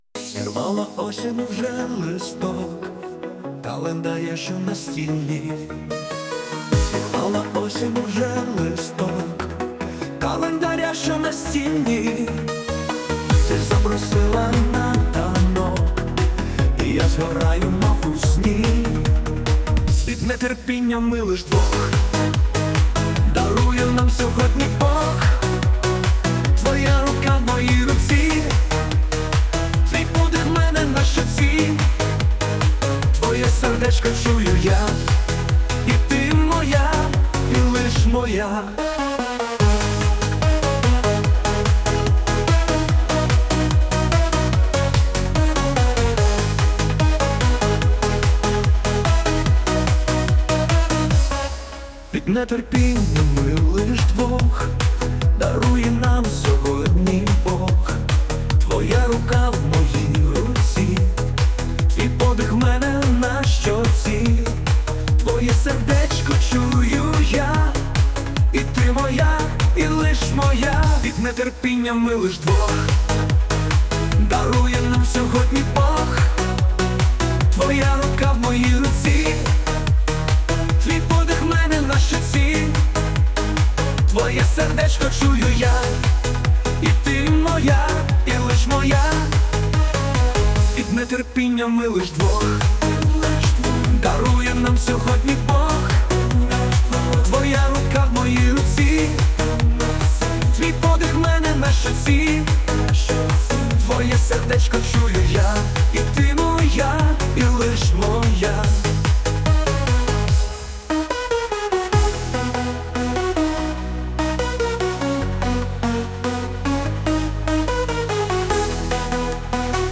ТИП: Пісня
СТИЛЬОВІ ЖАНРИ: Ліричний
Гарна лірична пісня і мелодія дуже гарна і рітмічна!